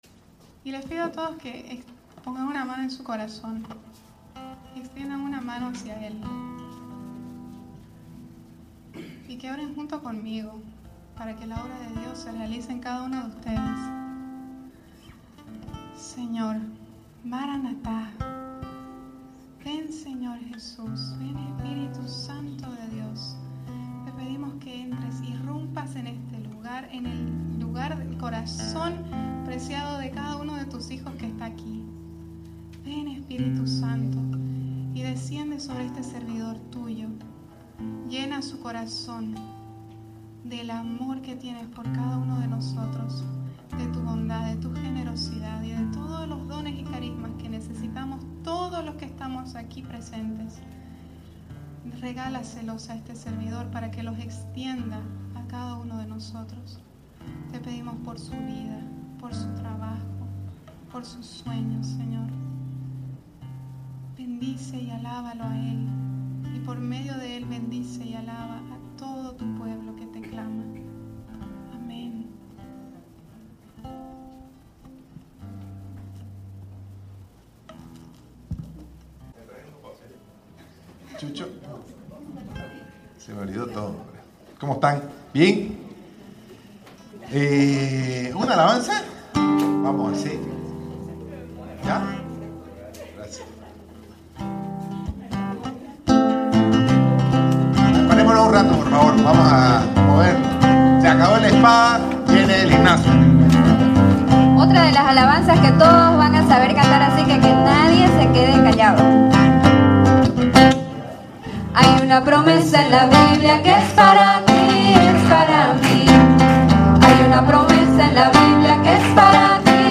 Predica de un Grupo de Oración Católico en Santa Cruz, Bolivia.